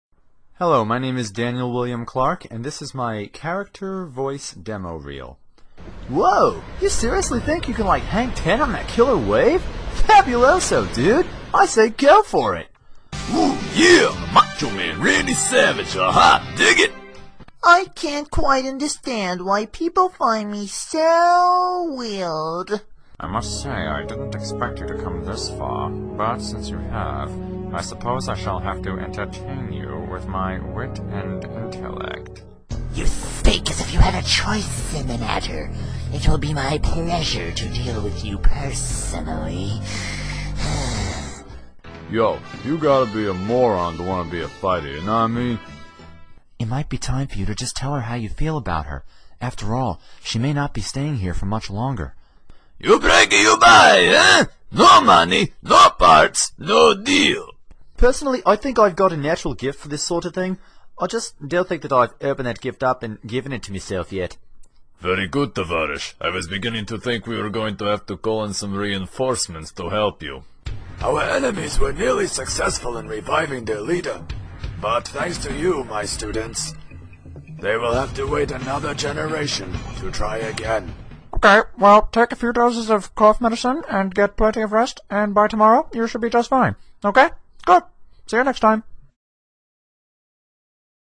Voice-over Demo Reels